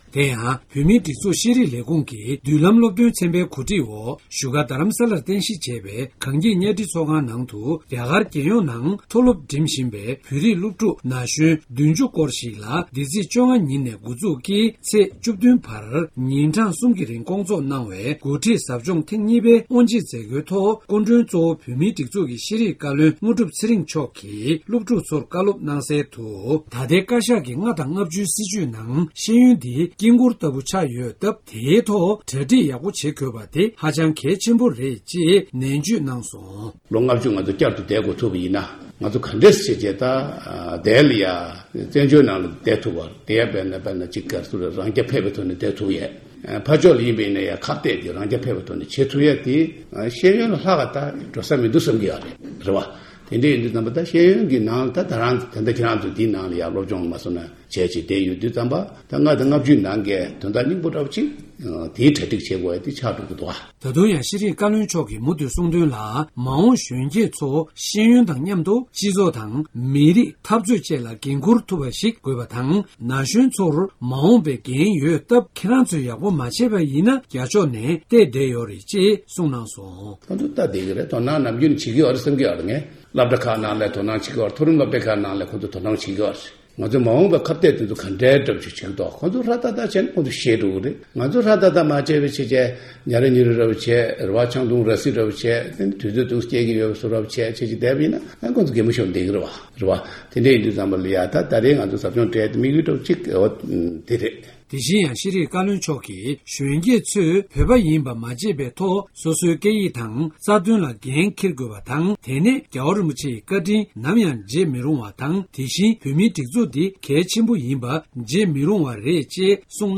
གསར་འགོད་པ